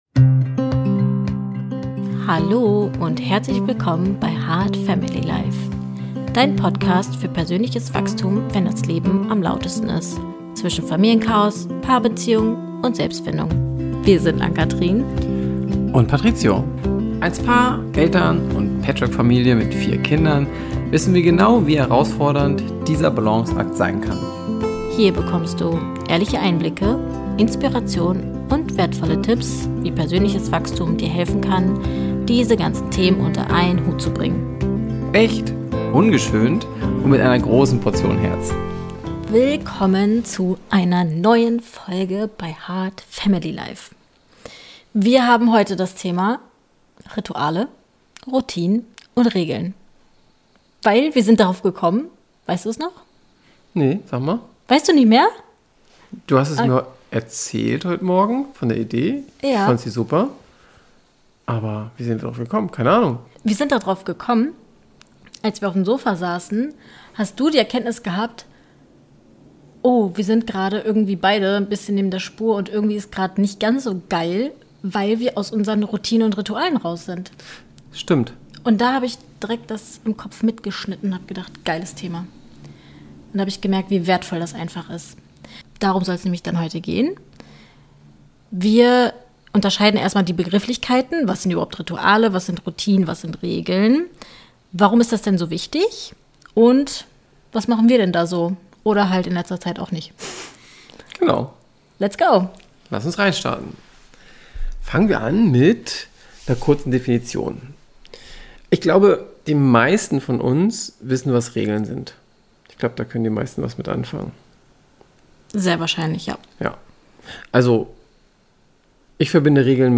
Ob Morgenritual, Einschlafroutine oder Familienregeln – wir sprechen ehrlich, humorvoll und reflektiert darüber, wie man zwischen Chaos und Liebe seinen eigenen Weg findet.